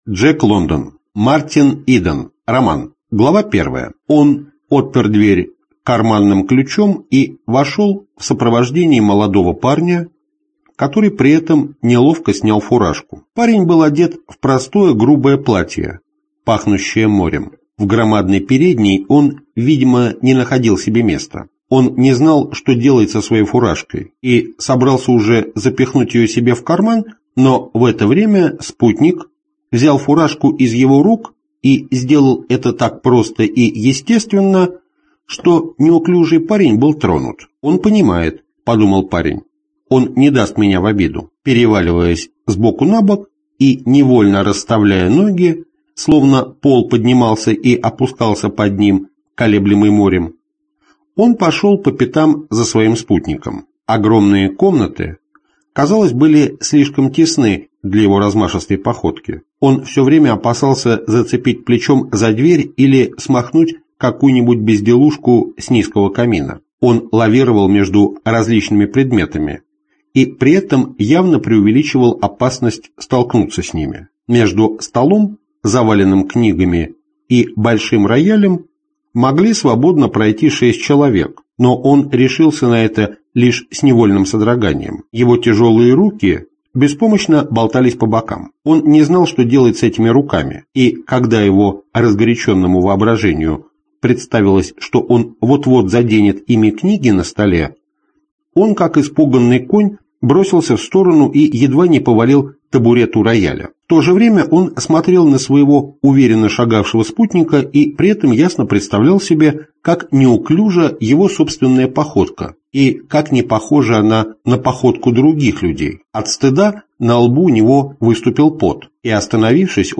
Аудиокнига Мартин Иден | Библиотека аудиокниг